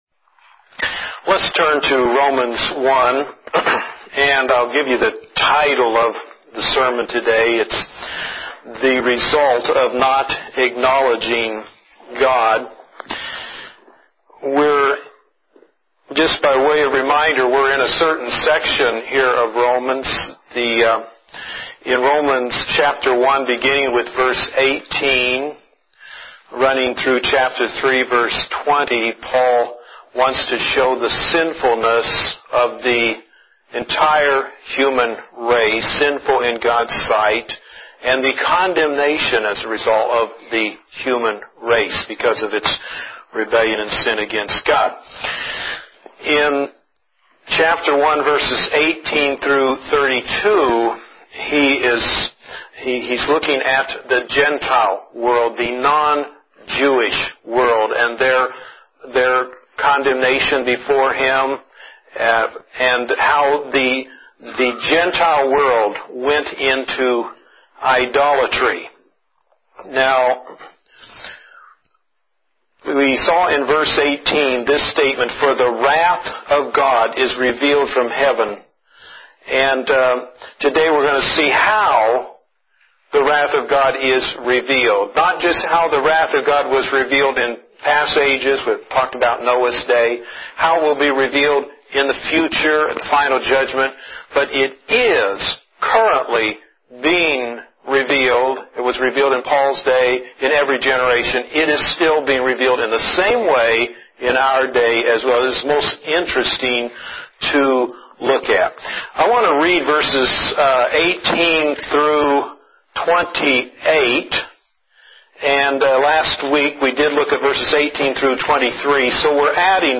2009 Sermons, Grace Baptist Church, Carlton, Yamhill County, Oregon